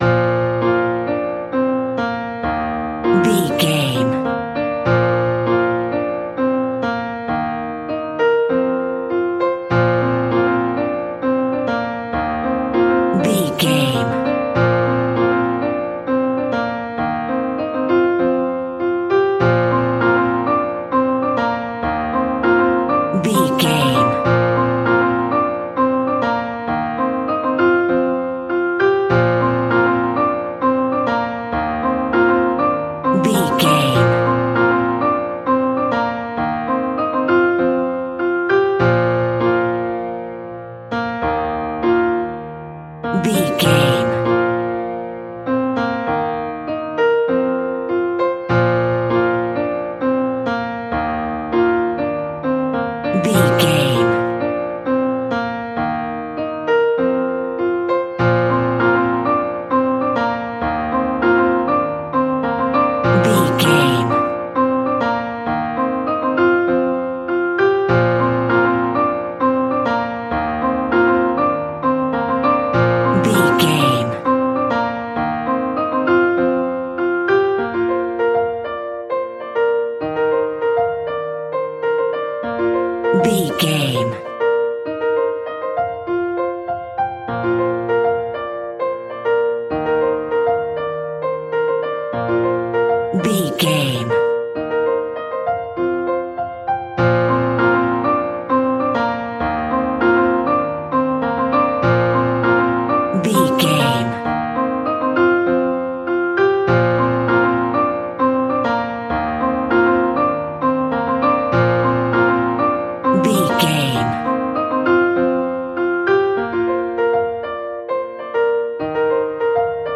Uplifting
Ionian/Major
B♭
childrens music
instrumentals
childlike
cute
happy
kids piano